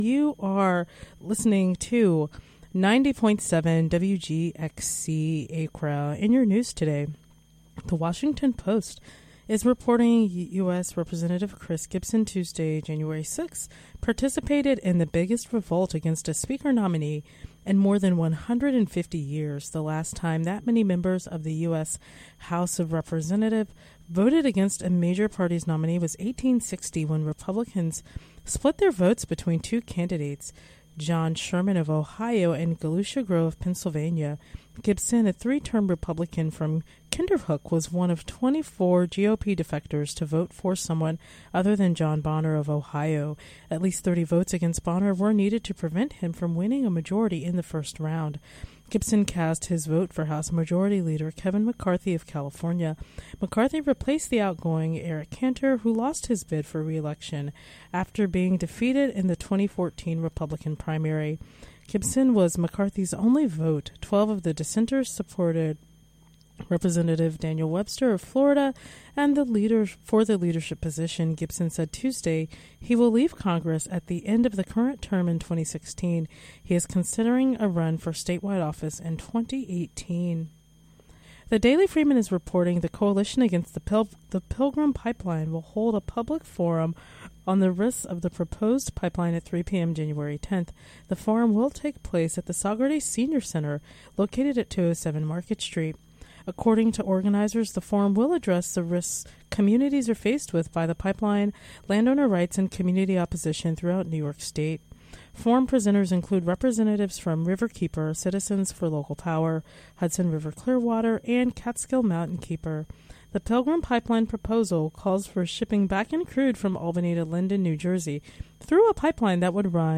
Local news and weather for Wednesday, January 7, 2015.